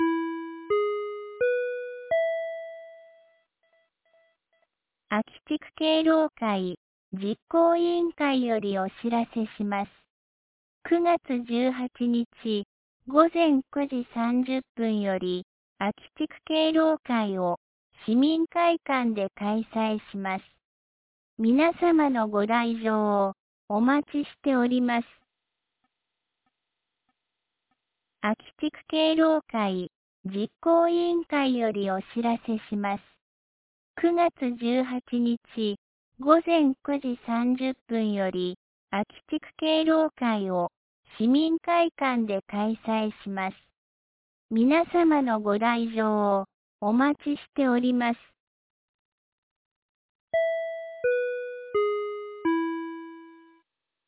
2025年09月17日 18時01分に、安芸市より安芸へ放送がありました。